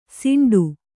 ♪ siṇḍu